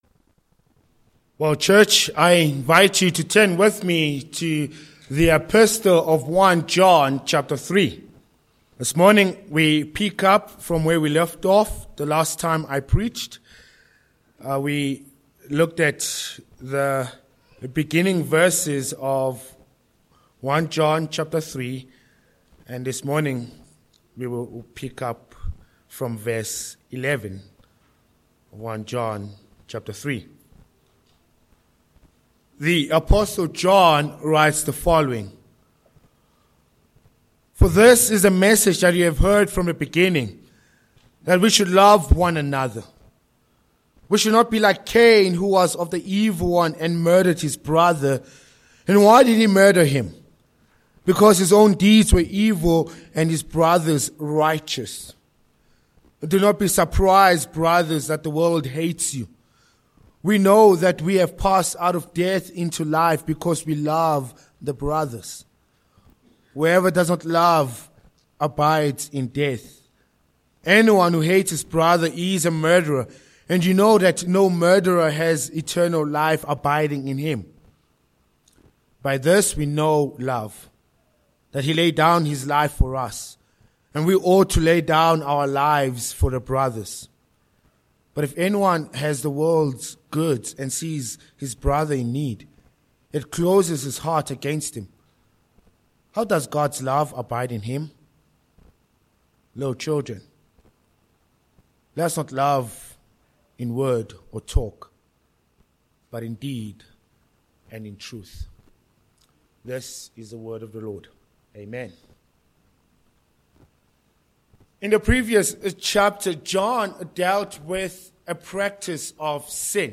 Sermon ponts: 1. Love One Another v11, v14
Service Type: Morning